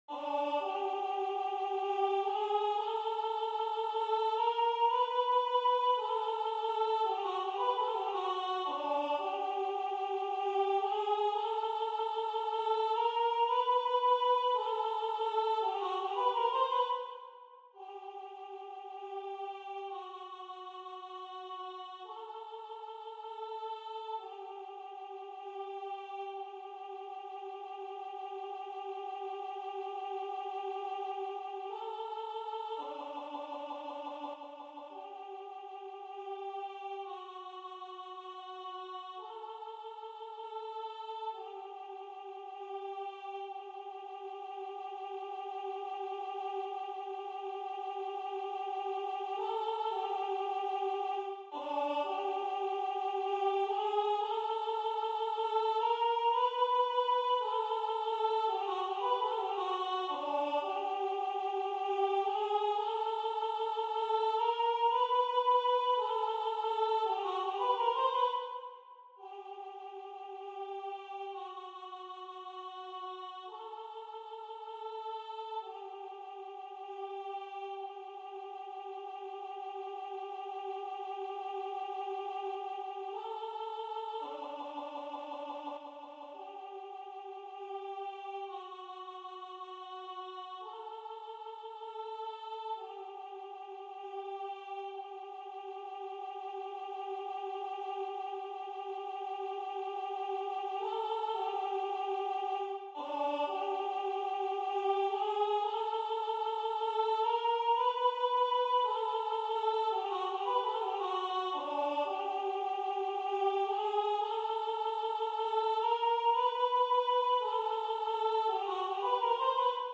Alto 1